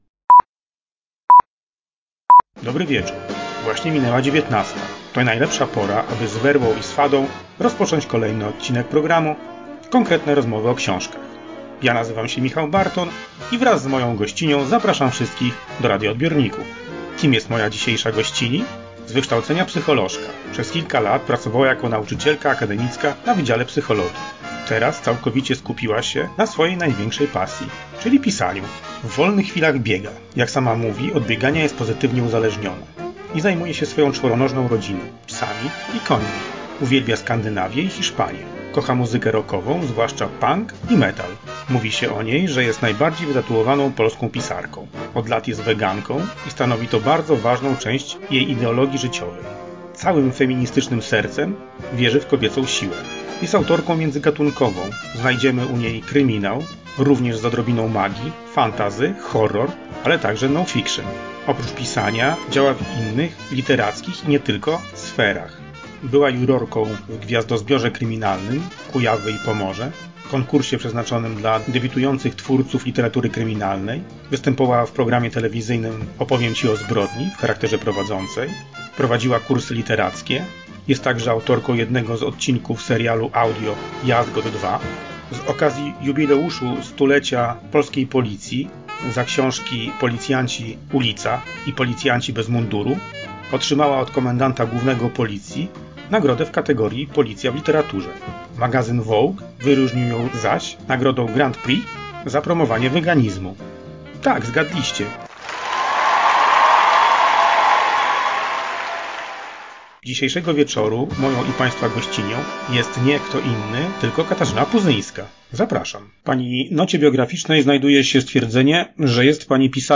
Magazyn Konkretne Rozmowy o Książkach gościł znaną pisarkę – Katarzynę Puzyńska